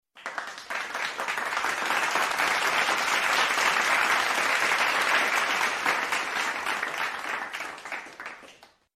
clapping